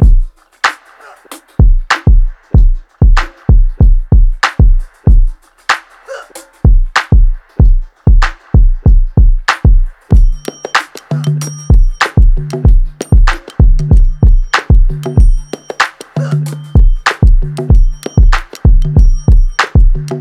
Loop Dad Drums.wav